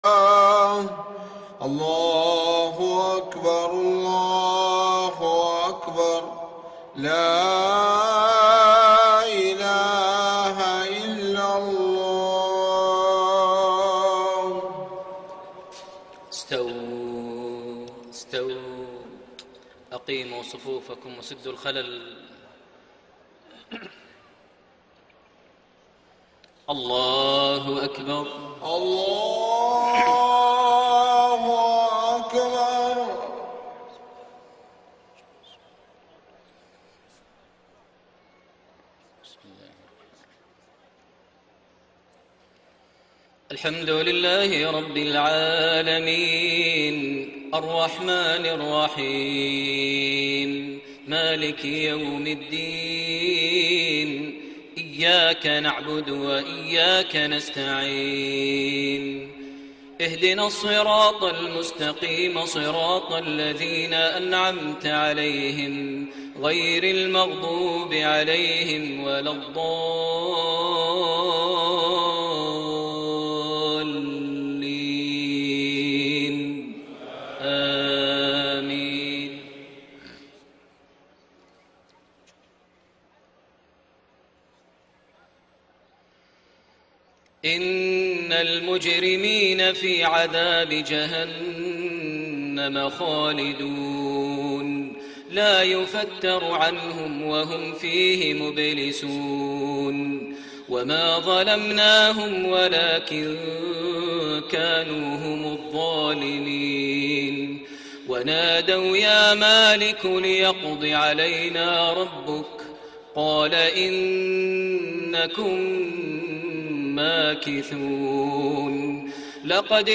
صلاة المغرب 8 ربيع الثاني 1433هـ خواتيم سورة الزخرف 74-89 > 1433 هـ > الفروض - تلاوات ماهر المعيقلي